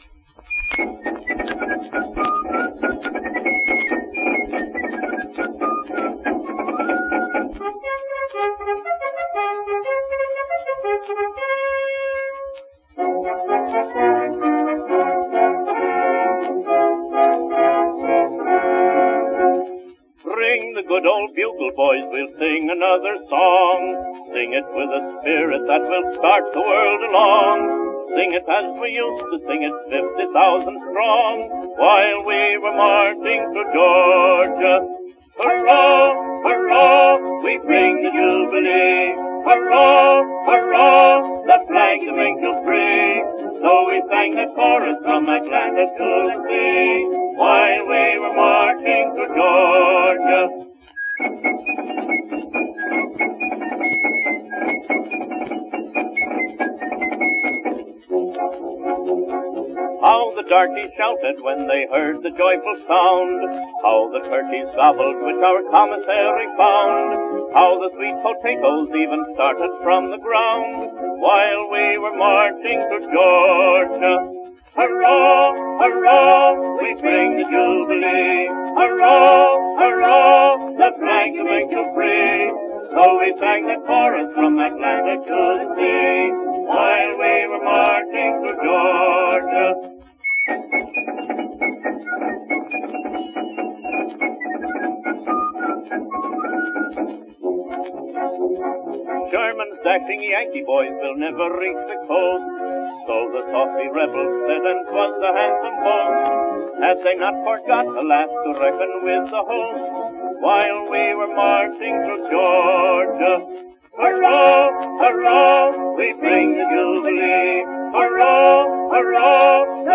Song, with chorus. Marching Thro’ Georgia
MarchingThroughGeorgia-harlanAndStanley.mp3